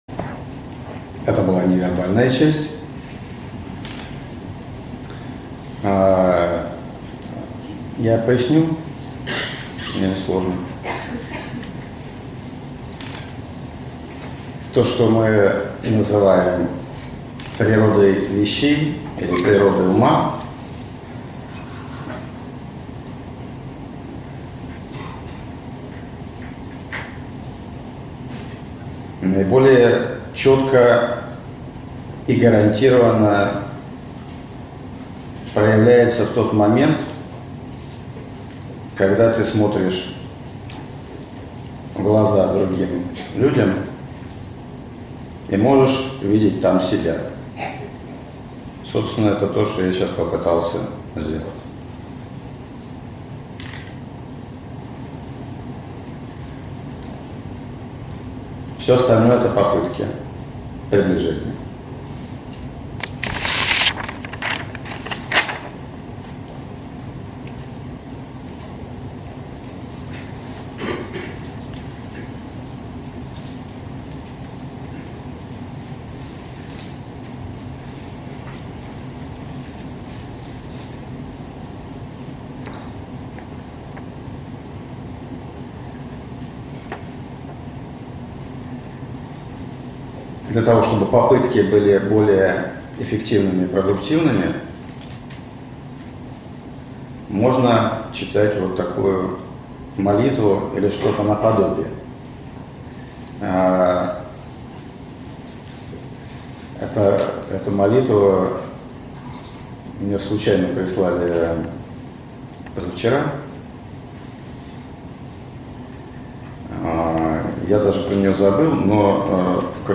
Аудиокнига Сущность бытия | Библиотека аудиокниг
Прослушать и бесплатно скачать фрагмент аудиокниги